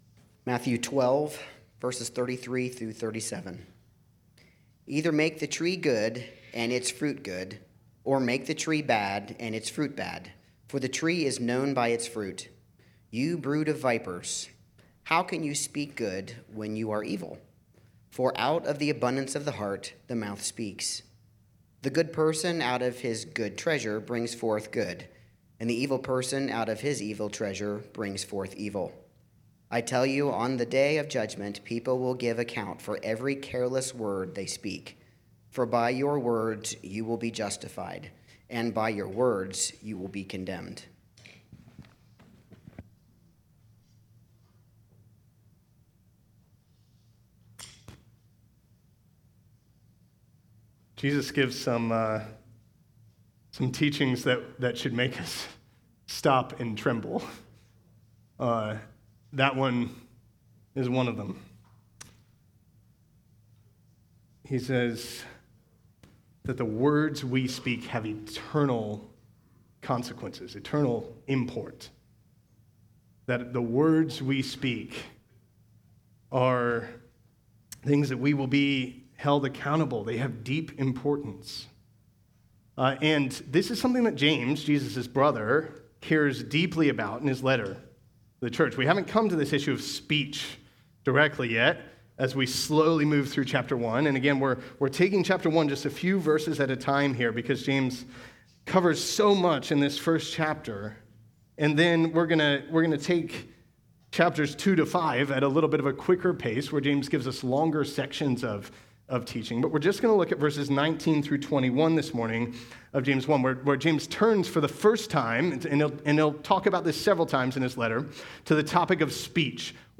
Sermons Archive - Christ Our Redeemer